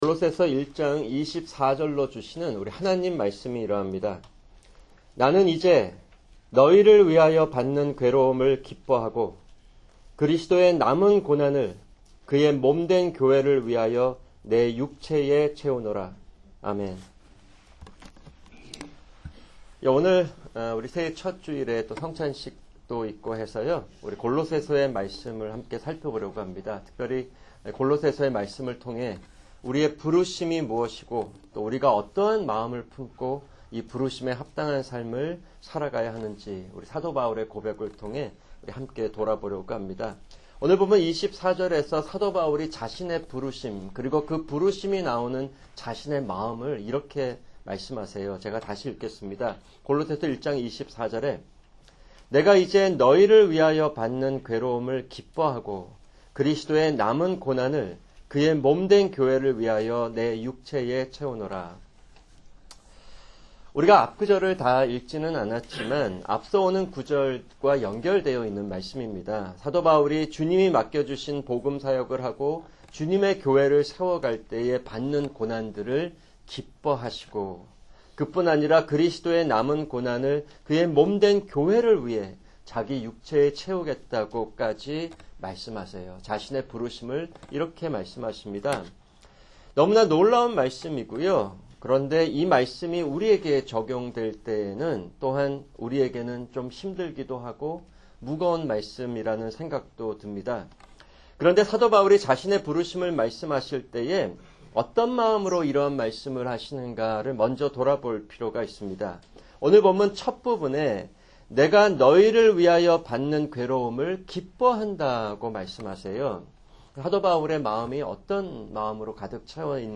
[주일 설교] 골로새서 2:4-7